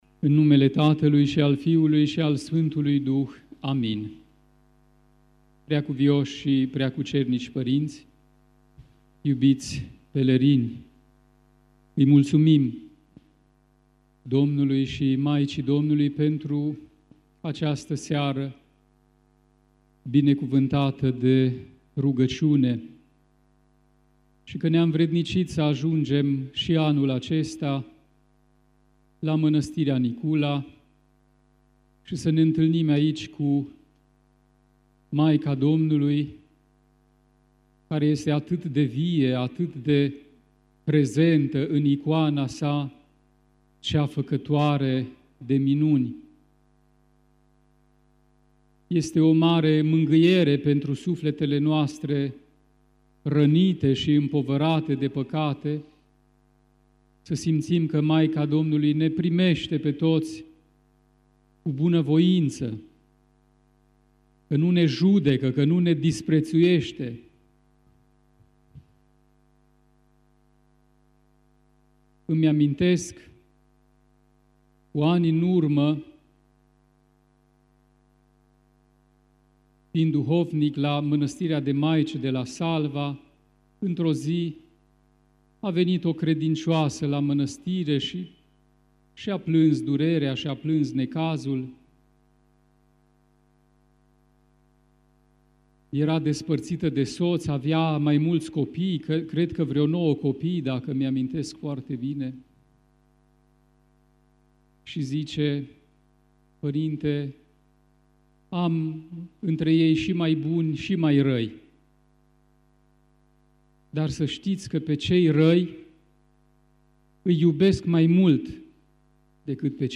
Cei sotiti s-au așezat pe dealul de lângă biserica veche de zid și asistă la slubele ce se oficiază aproape non-stop pe scena amenajată în curtea așezământului monahal.